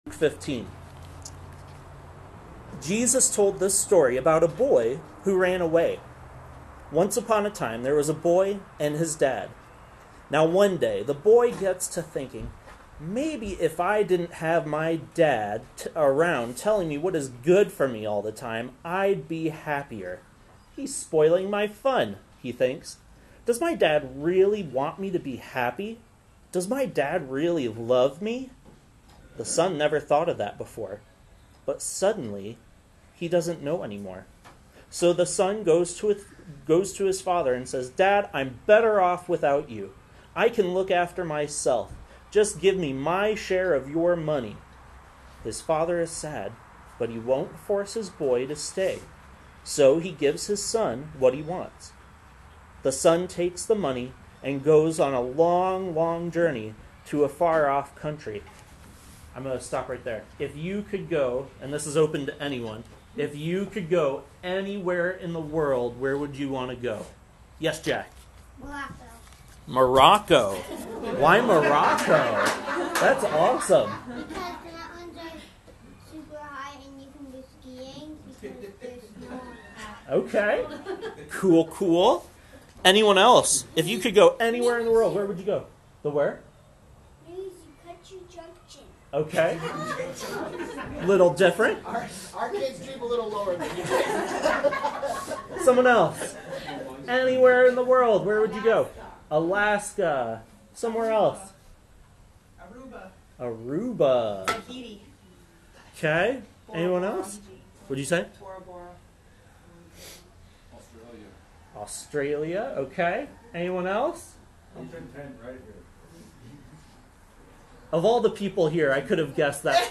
From our church picnic, we briefly look at the parable of the lost son.